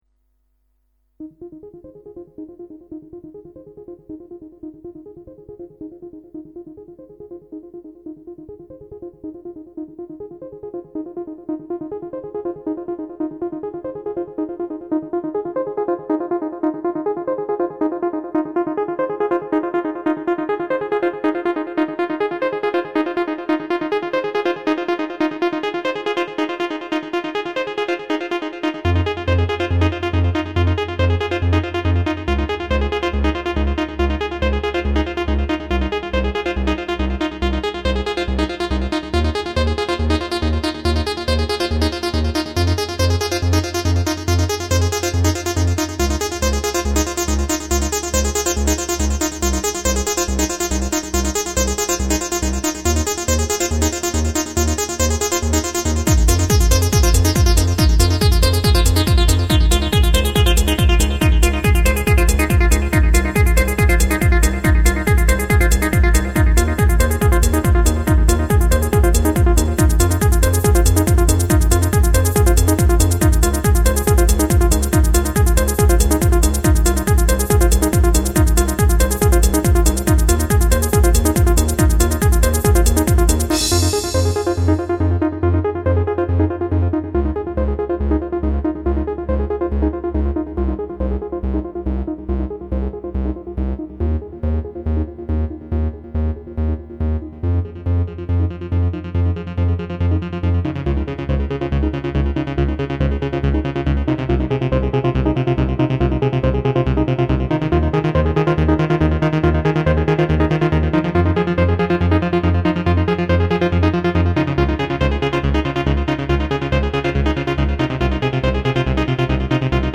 short trance mix
• Jakość: 44kHz, Stereo